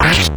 Play, download and share enemyhit original sound button!!!!
enemyhit.mp3